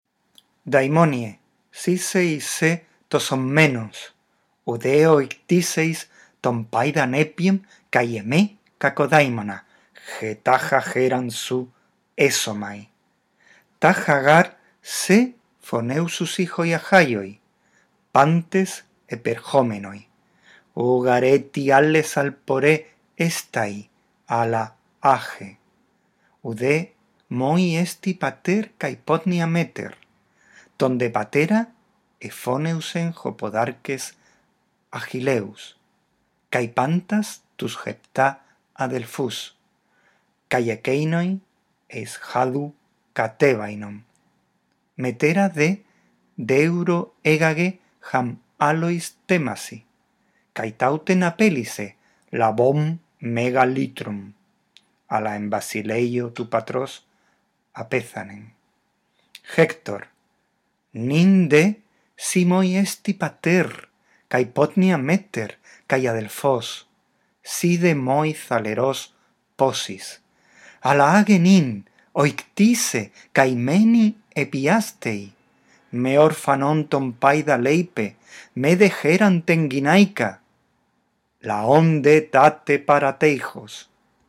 Lee el texto en voz alta, respetando los signos de puntuación. Después escucha estos tres archivos de audio y repite la lectura.